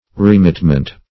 Remitment \Re*mit"ment\ (-ment), n.